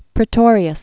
(prĭ-tôrē-əs), Andries Wilhelmus Jacobus 1798-1853.